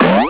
bweeeer.au